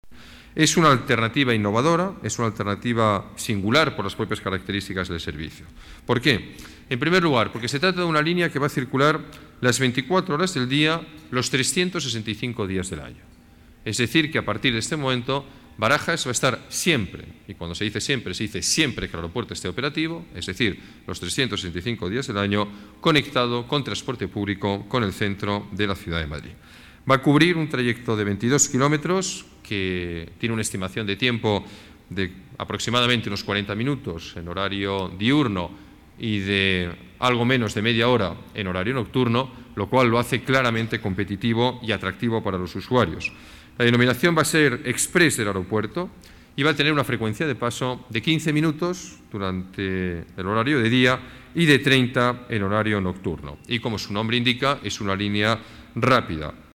Nueva ventana:Declaraciones del alcalde: características de la nueva línea